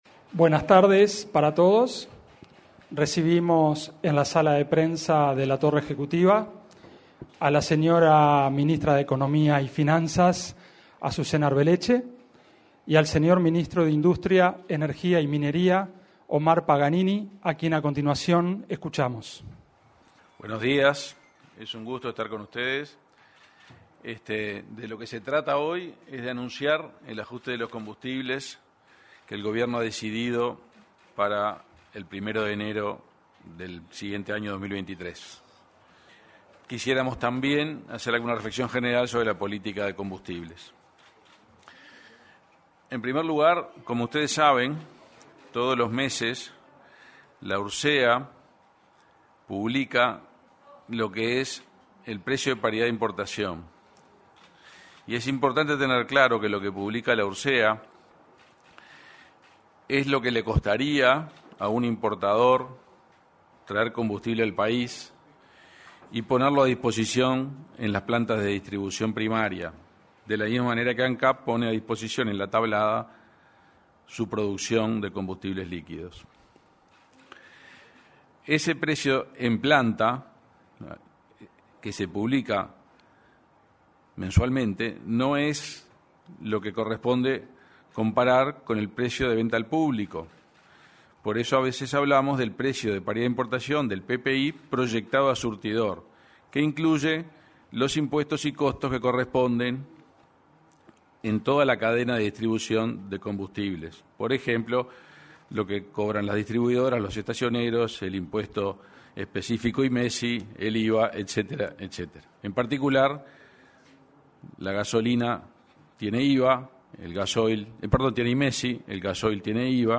Conferencia de prensa para anunciar baja de tarifas de combustibles
Conferencia de prensa para anunciar baja de tarifas de combustibles 29/12/2022 Compartir Facebook X Copiar enlace WhatsApp LinkedIn Este jueves 29, se realizó una conferencia de prensa para anunciar la baja en las tarifas de combustibles. Participaron el ministro de Industria, Energía y Minería, Omar Paganini, y la ministra de Economía y Finanzas, Azucena Arbeleche.